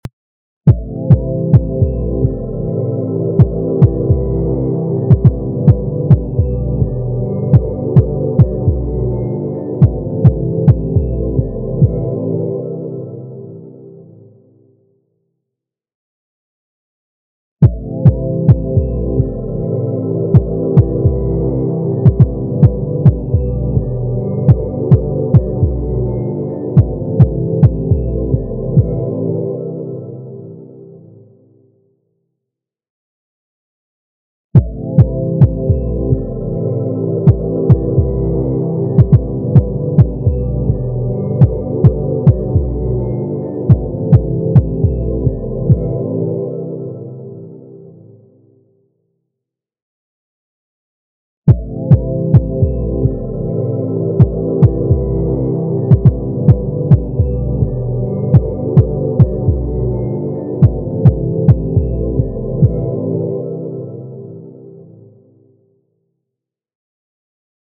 Информация Закрыть Загружено 06.08.2021 14:15 Размер 2.6 MB Просмотров 200 Последний Вчера в 07:00 Время 1мин.7сек. Кодек mp3 Битрейт 320 кбит/сек Частота дискретизации 44.1 КГц MD5 Cuted drums